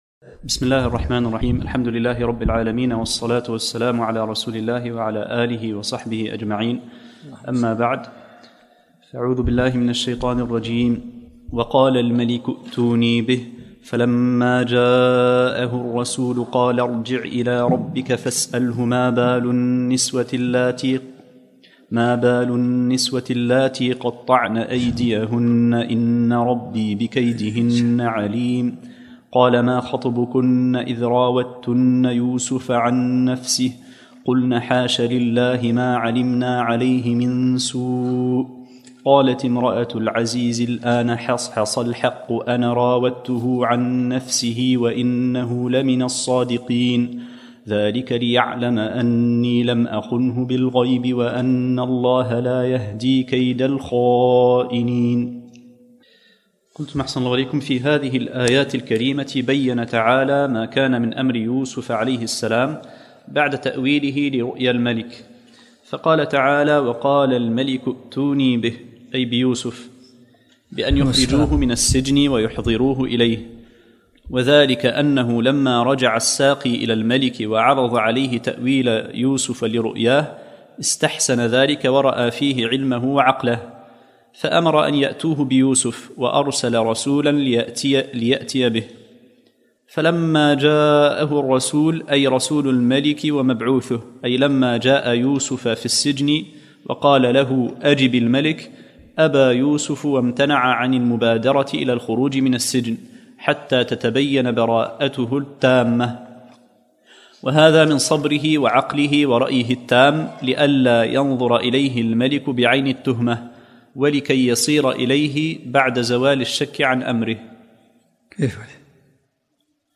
الدرس العاشرمن سورة يوسف